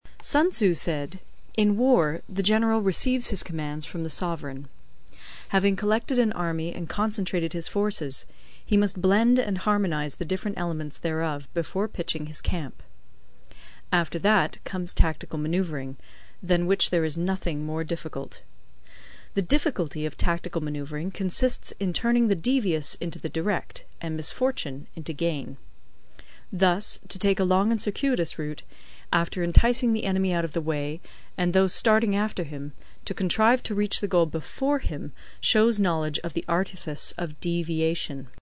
有声读物《孙子兵法》第39期:第七章 军争(1) 听力文件下载—在线英语听力室